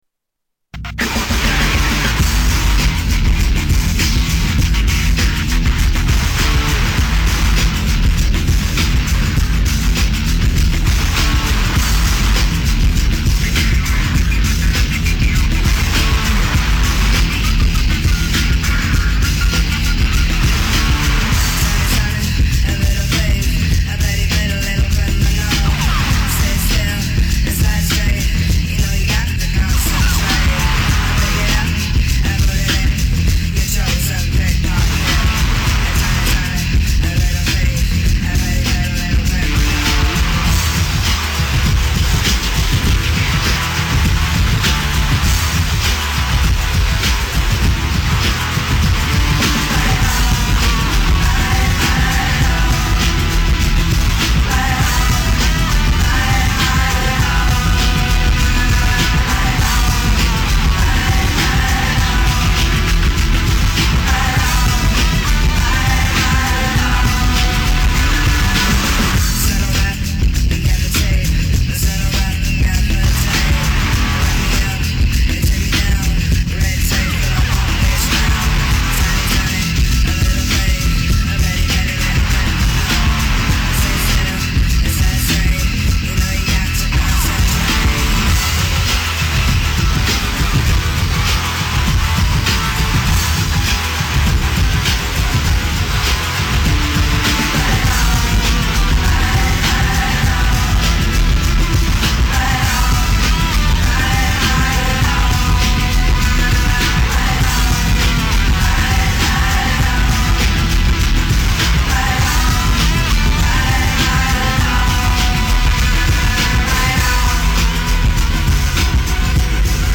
Tags: Music Spy Movie Music Espionage Movies Movie Themes